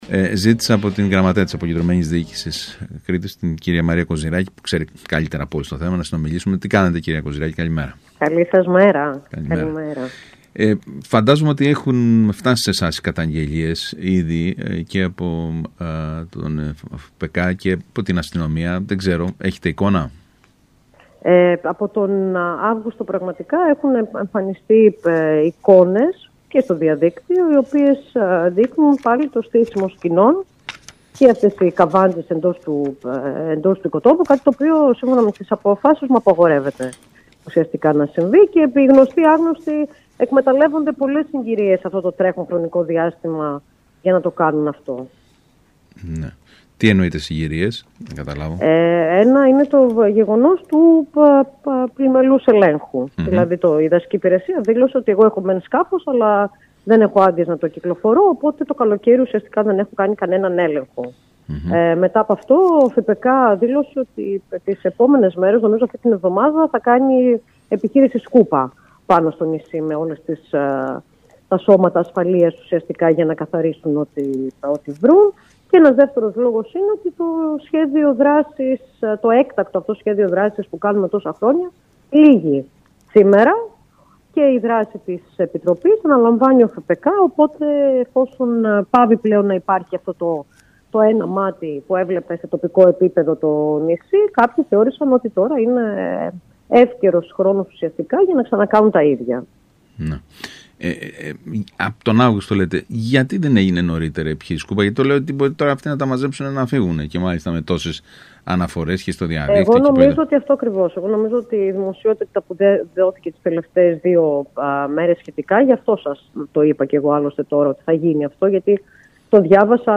Η κ. Κοζυράκη μίλησε την Τετάρτη 17 Σεπτεμβρίου στον ΣΚΑΙ Κρήτης 92.1 και στην εκπομπή